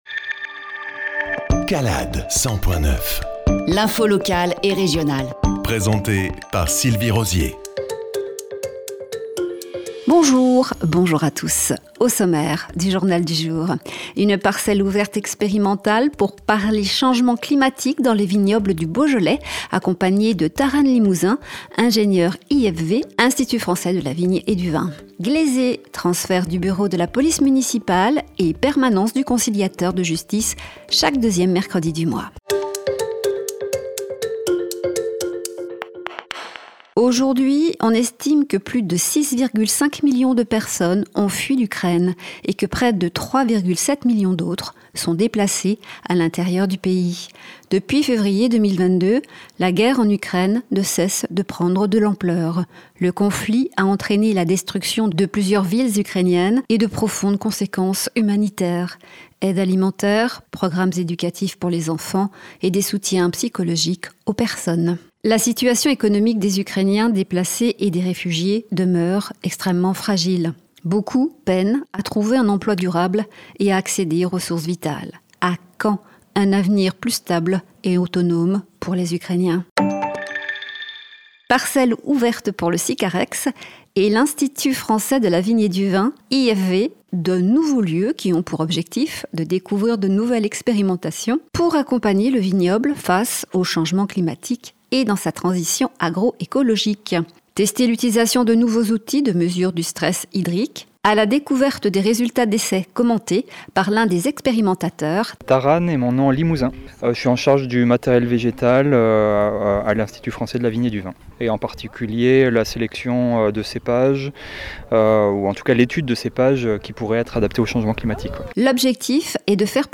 Journal du 21-02-25